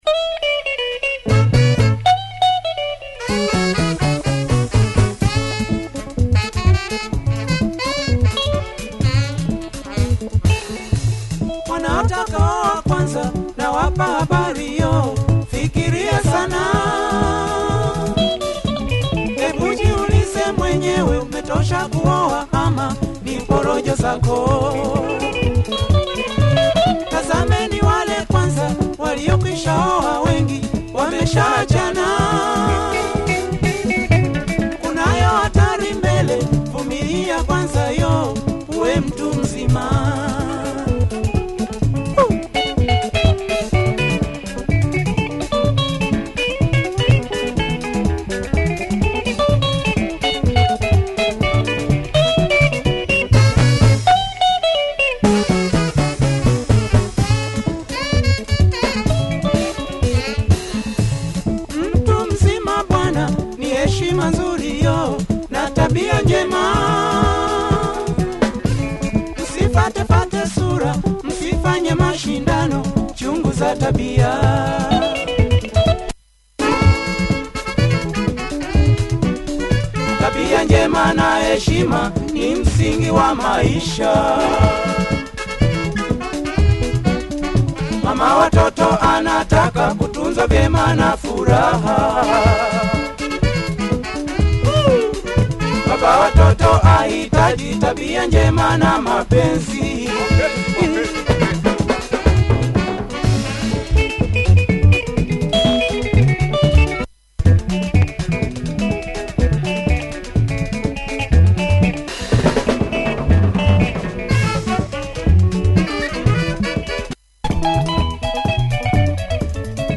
good tempo and guitar juicing his own path. Nice sax too!